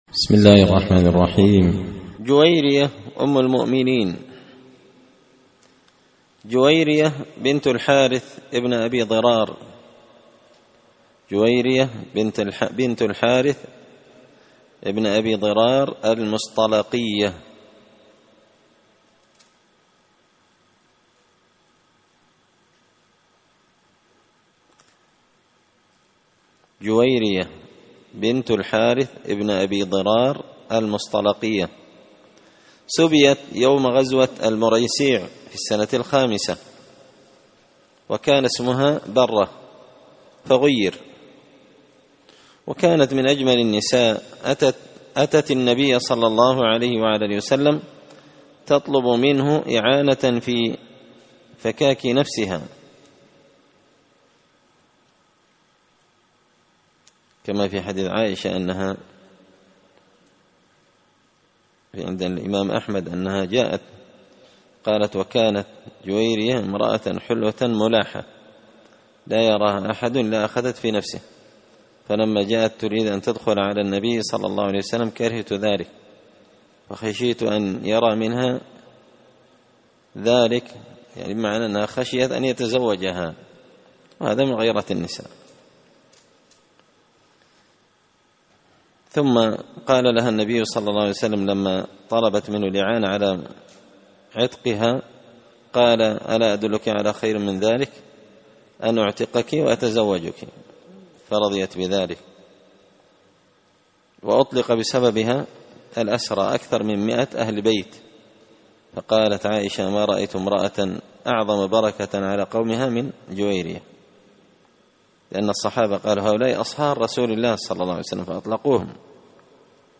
قراءة تراجم من تهذيب سير أعلام النبلاء
دار الحديث بمسجد الفرقان ـ قشن ـ المهرة ـ اليمن